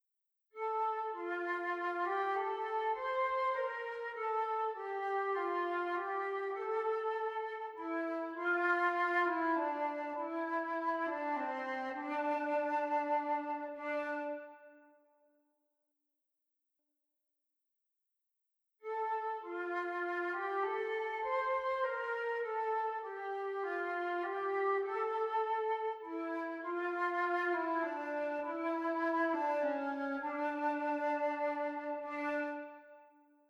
Vergelijk bijvoorbeeld onderstaande melodietjes met elkaar: het is duidelijk te horen dat het tweede melodietje duidelijker, 'krachtiger' afsluit dan het eerste.
natuurlijk-en-harmonisch.mp3